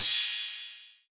Official Trap Crashes (4).wav